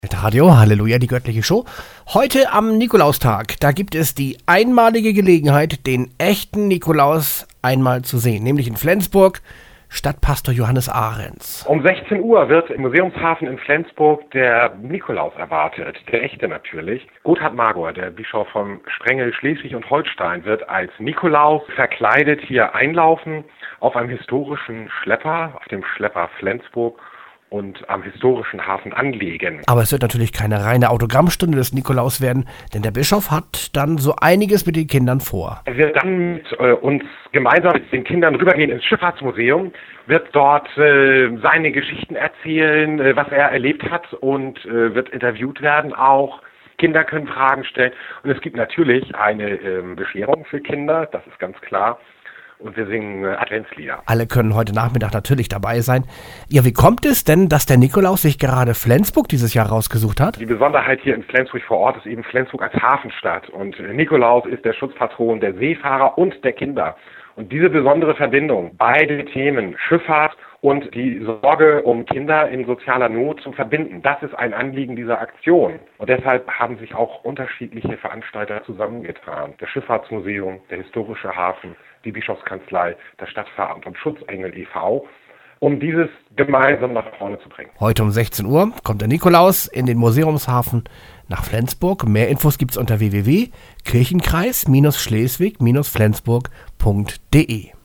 Radiobeitrag zum Nikolaus-Besuch: